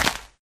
grass3.ogg